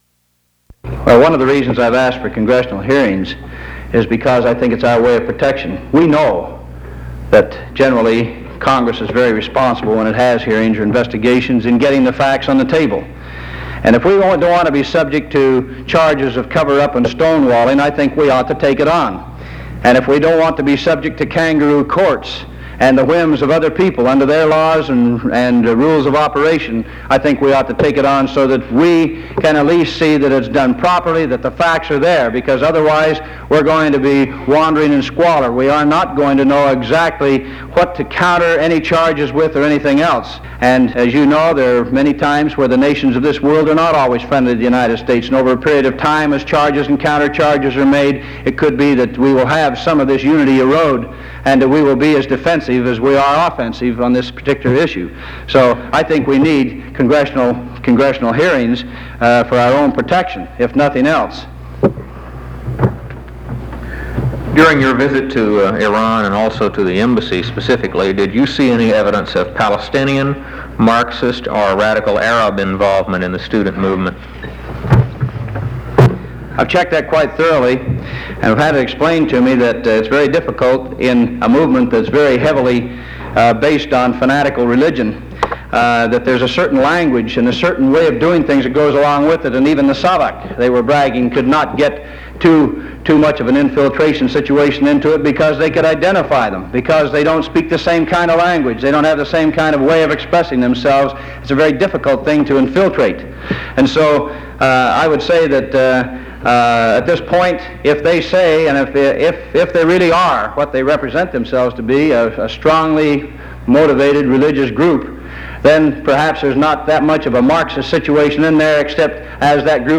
Broadcast on NPR, December 14, 1979.